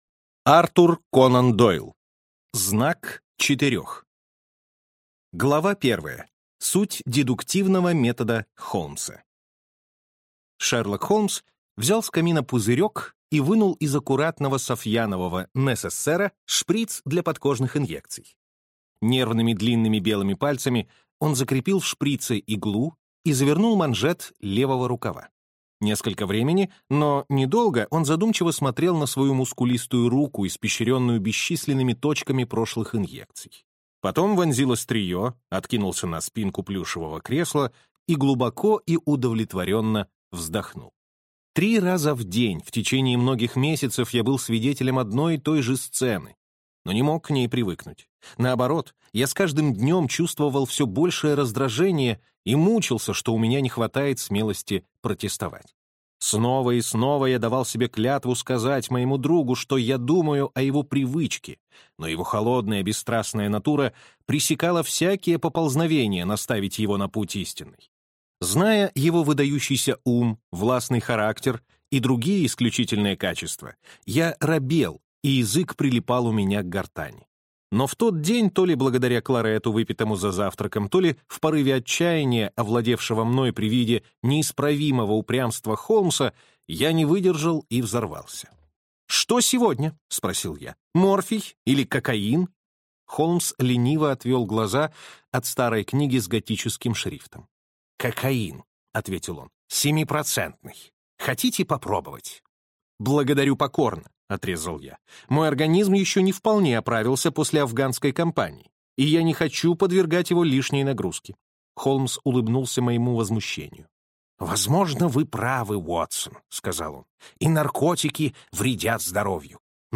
Аудиокнига Знак четырех | Библиотека аудиокниг